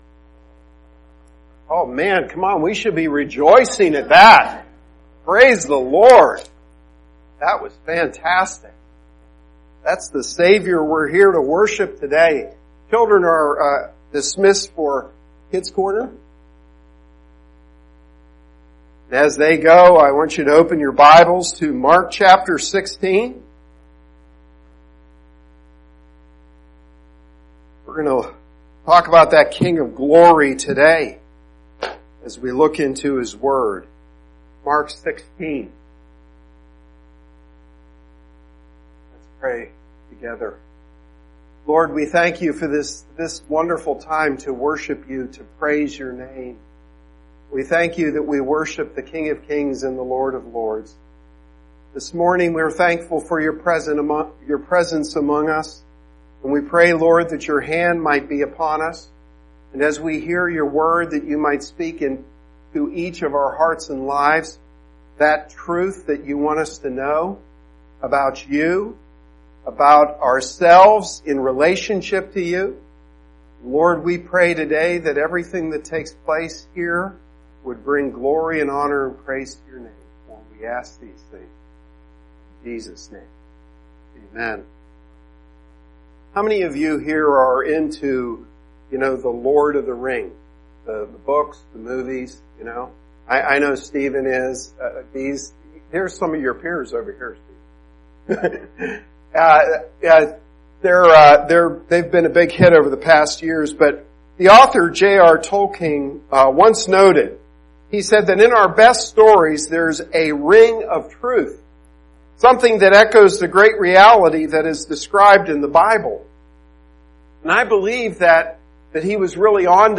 FAC-Sermon-8-12-18.mp3